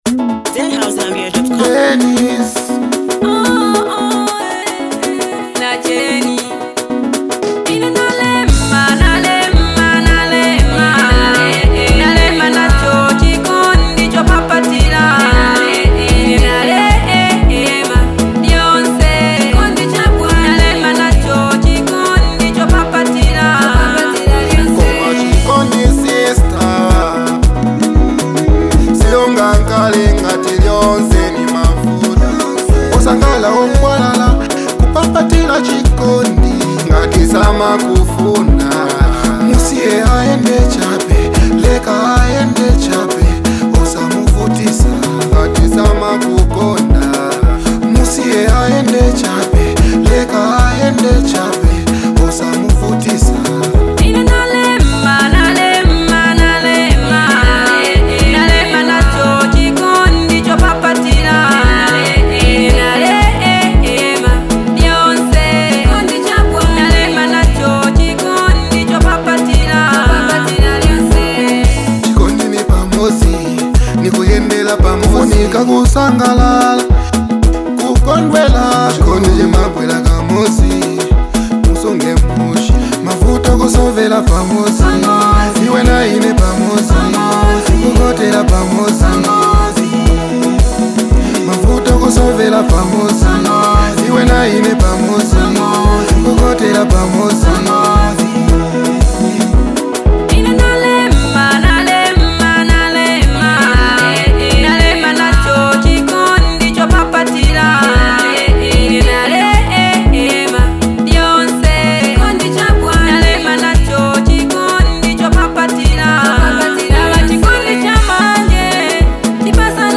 Zambian song
soothing vocals
a melodic beat